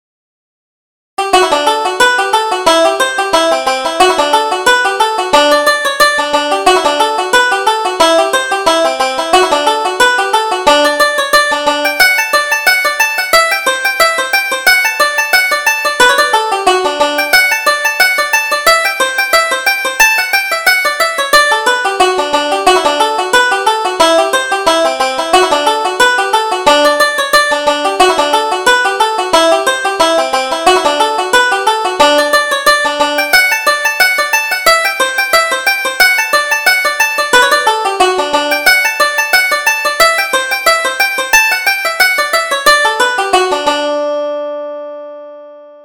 Reel: Paddy Murphy's Wife